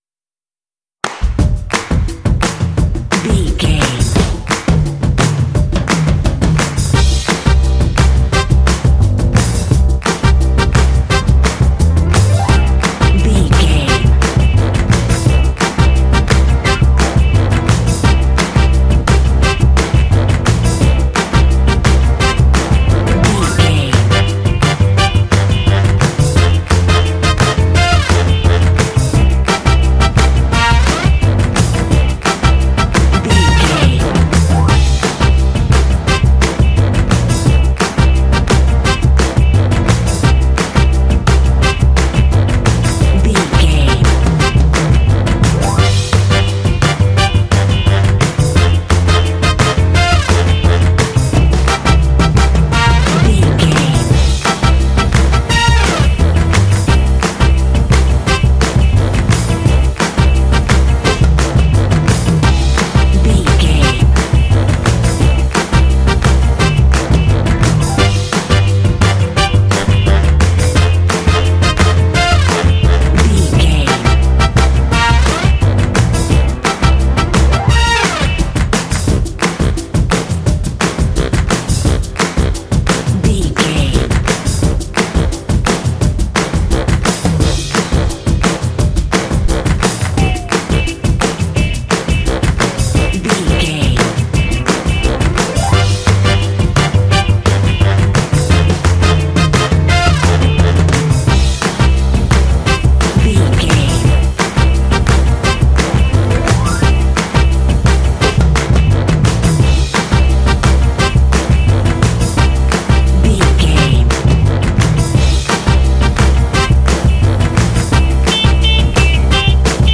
Ionian/Major
groovy
bouncy
cheerful/happy
drums
percussion
bass guitar
piano
electric guitar
brass
blues
jazz